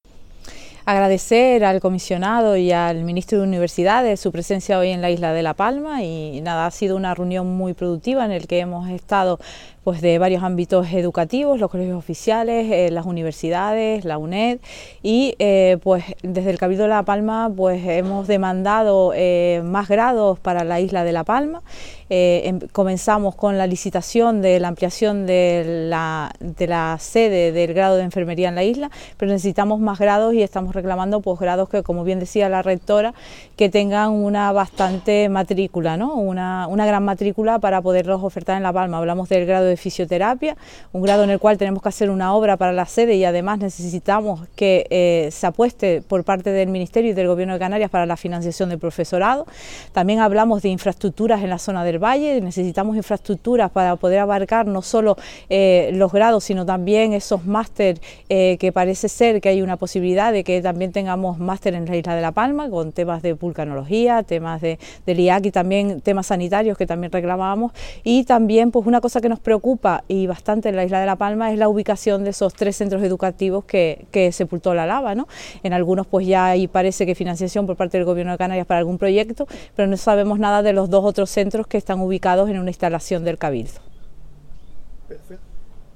Declaraciones audio Susana Machín Universidades.mp3